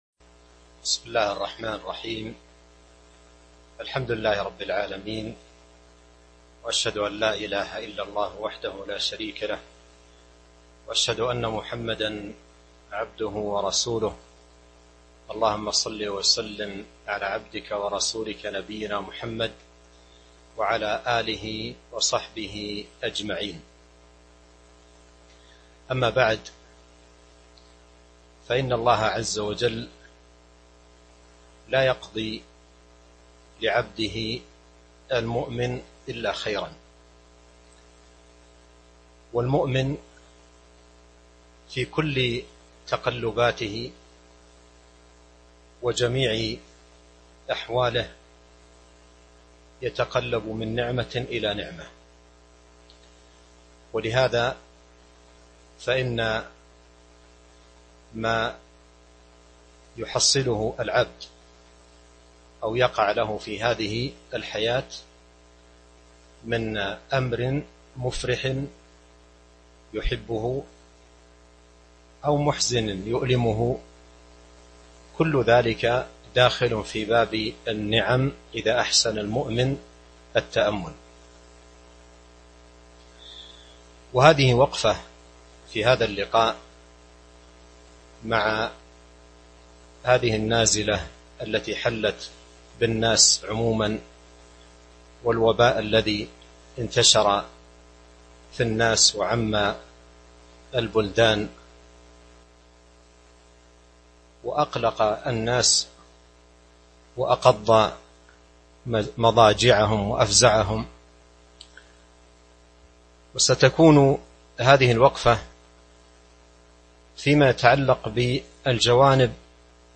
تاريخ النشر ٥ ذو القعدة ١٤٤١ هـ المكان: المسجد النبوي الشيخ